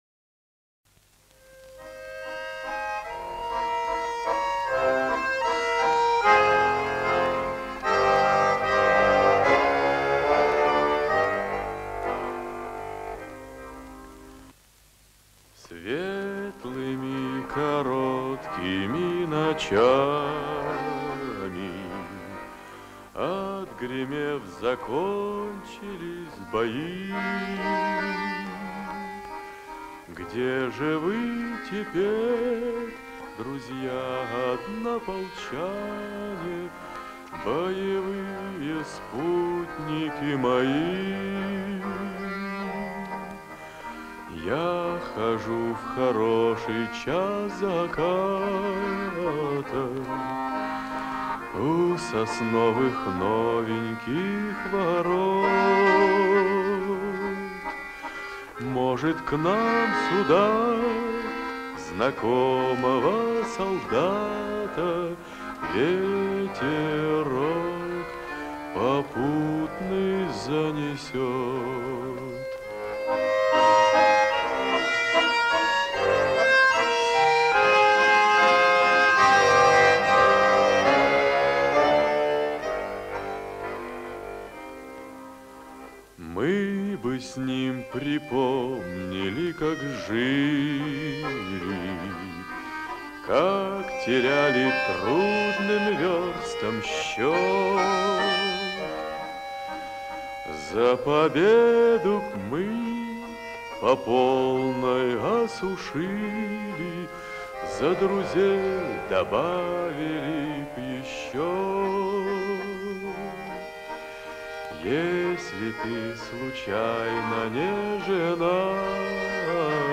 соединил вступление из начала с песней из окончания...